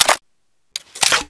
ar2_reload_rotate.wav